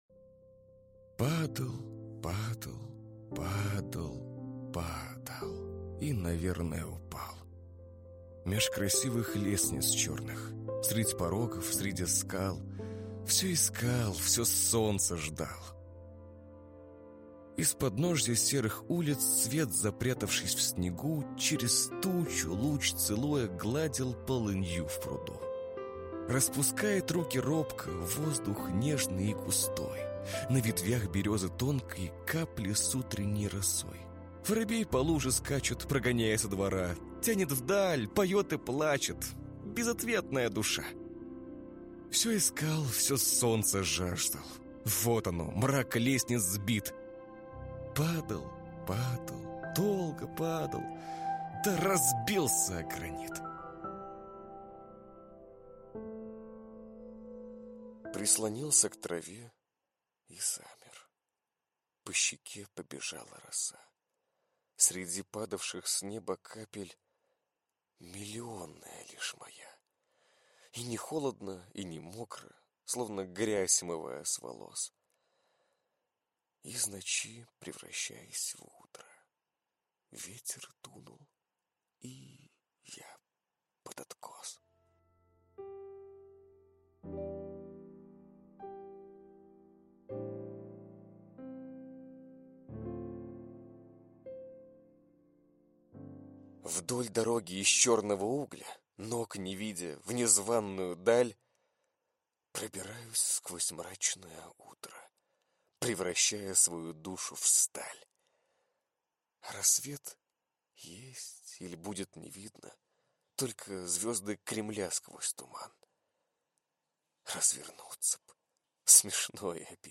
Аудиокнига Полёт под небом | Библиотека аудиокниг